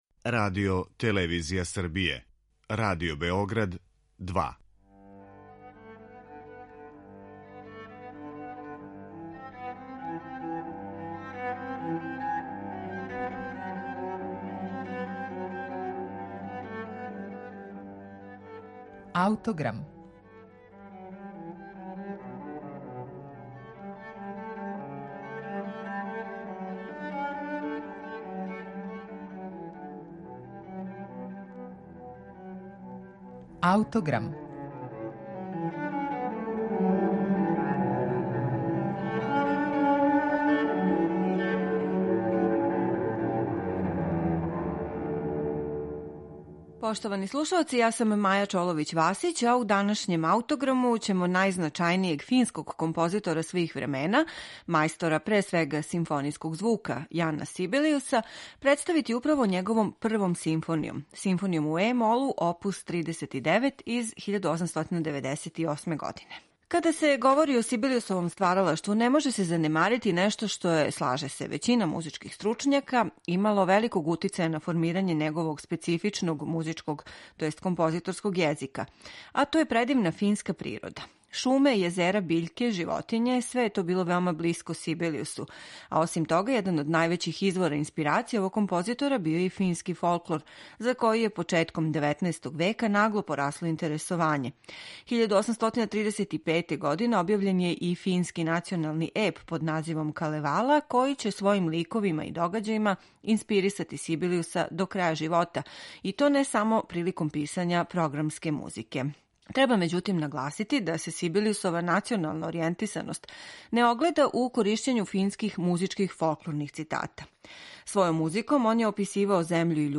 Соло песме Зигфрида Вагнера